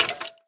skeletonhurt2.ogg